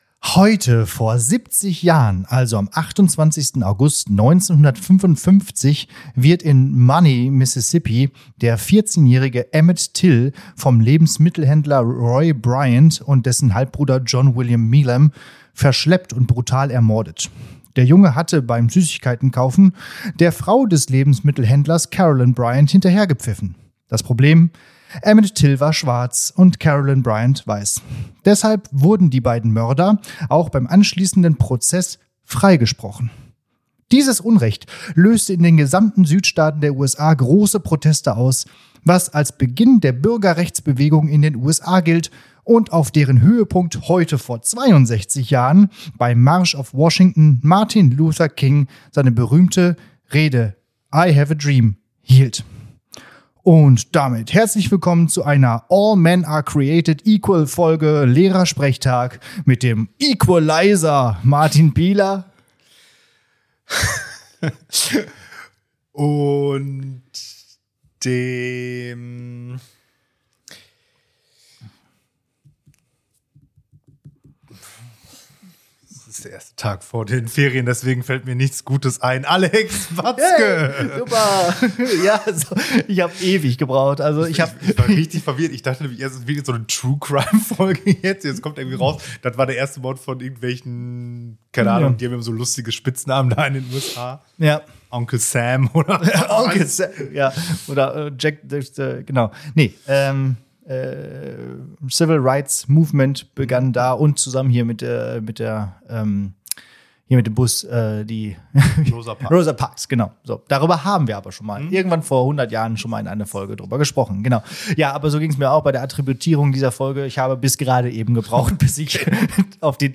Für die Studienräte bedeutet dies nicht nur einen Konferenzmarathontag, sondern auch die Gelegenheit, vis-à-vis in der Schule eine neue Folge Lehrersprechtag aufzunehmen.